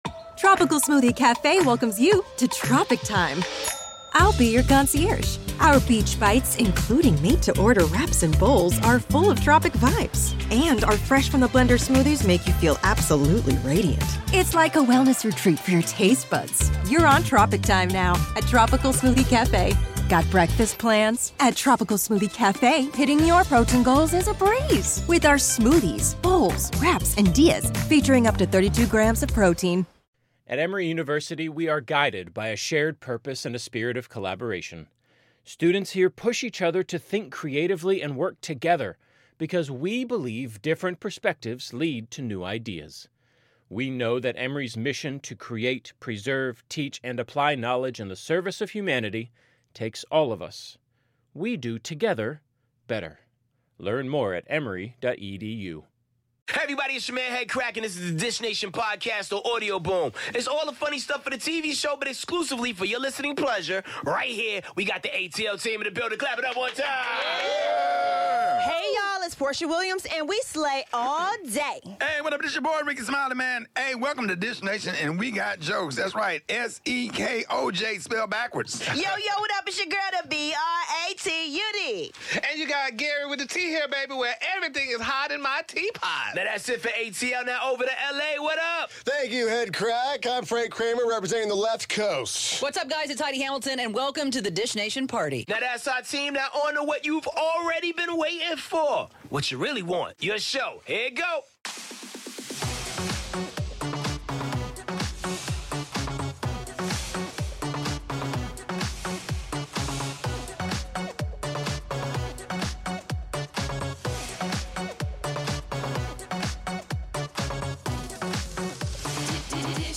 Meagan Good and Michael Ealy are in-studio dishin' on their new film 'Intruders' 🎥 Jennifer Lopez is in double trouble 👫😳 and Kit Harington reveals a secret about Jon Snow 😱 All this and more on today's Dish Nation!